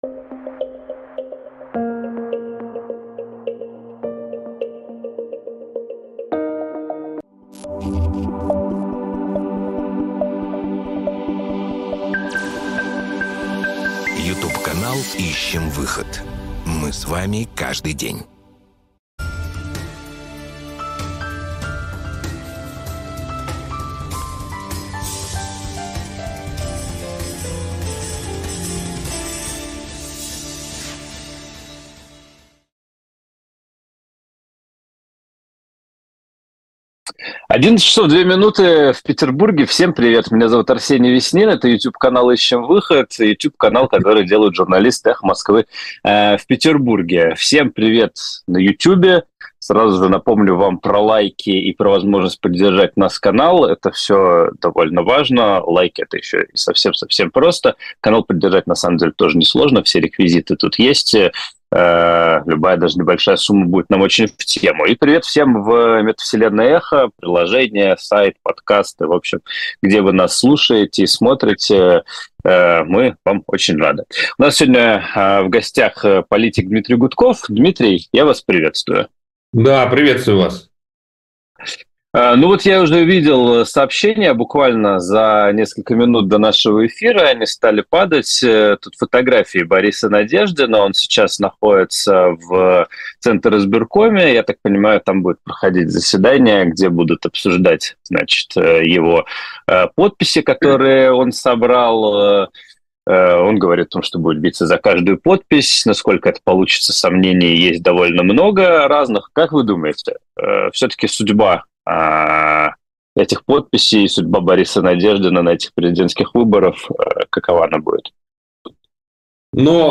Дмитрий Гудков политик